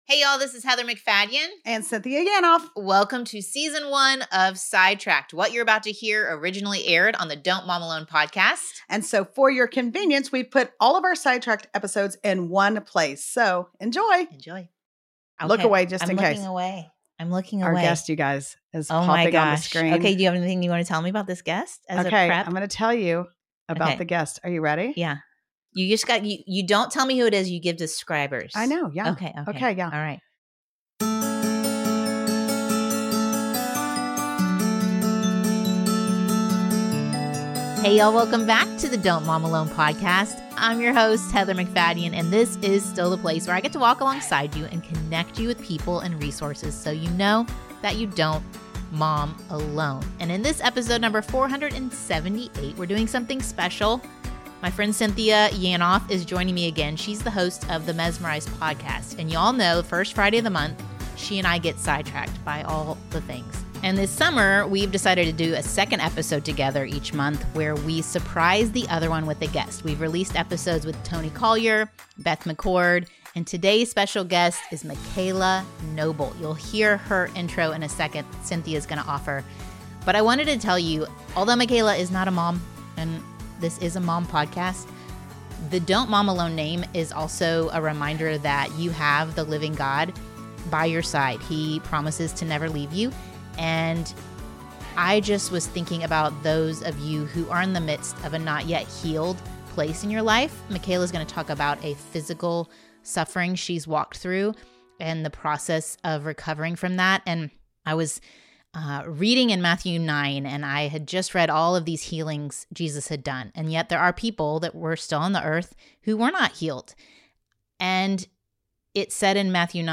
Today we have the honor of interviewing her to see how God has used her life to glorify Him even when things don’t go as planned.